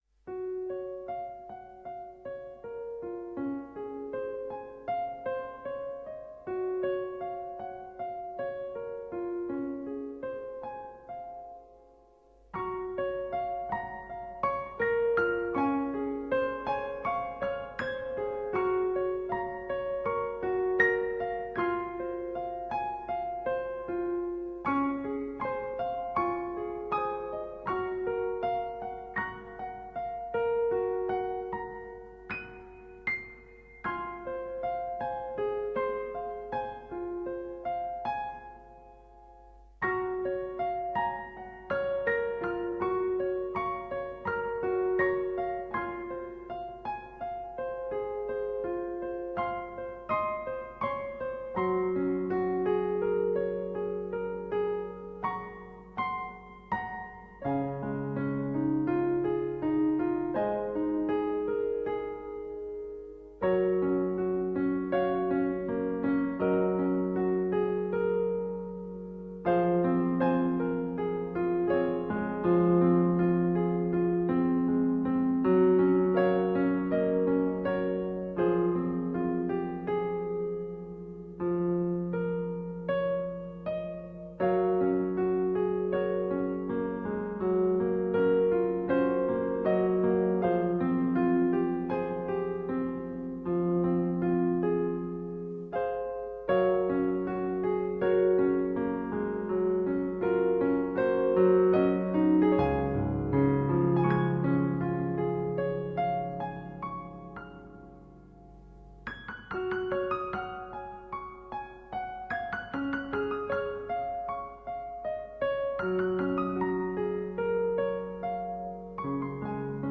a solo piano work
a 1 hour album of relaxing solo piano music.